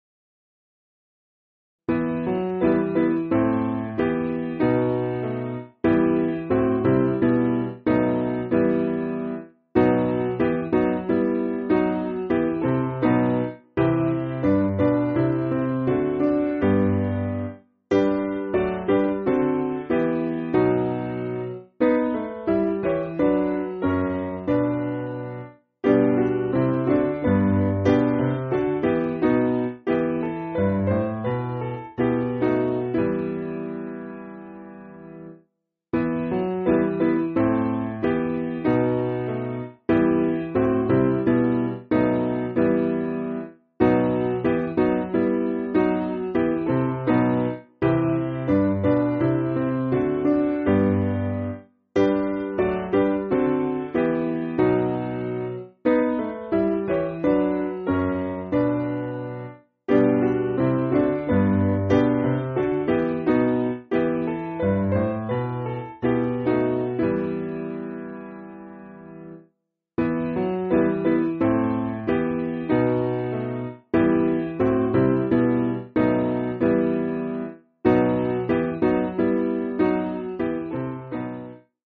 Simple Piano
(CM)   4/Em